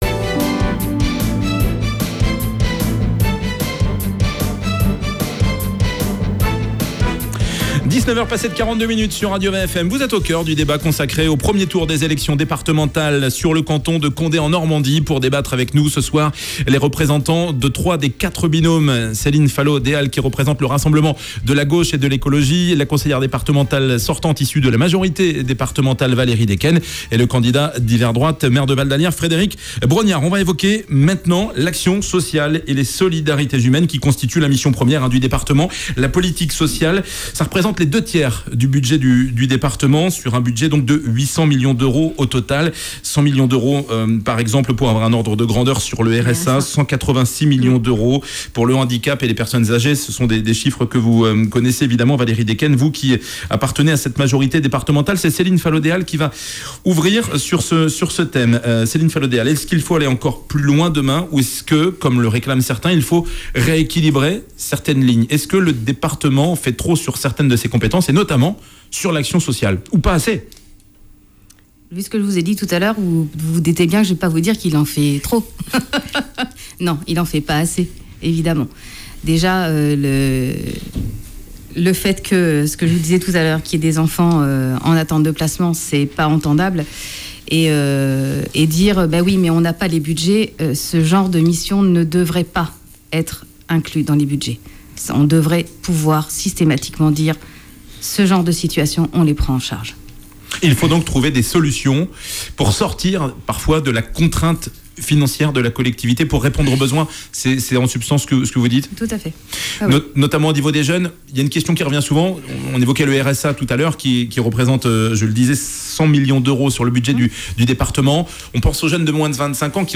Débat Condé en Normandie